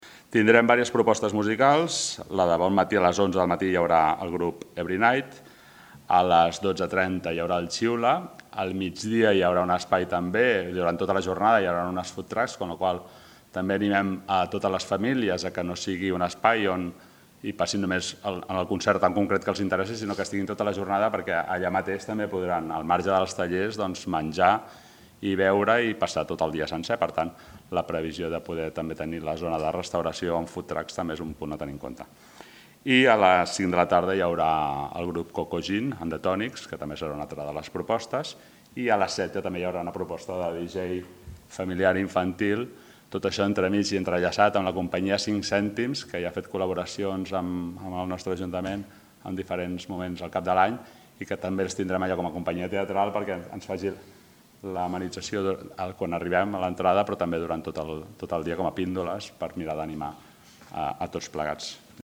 Presentació El Petit
Sergi Corral, regidor de Cultura